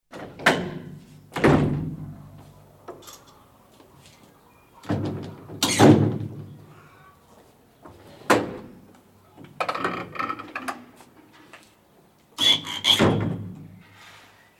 Hoftor.mp3